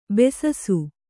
♪ besasu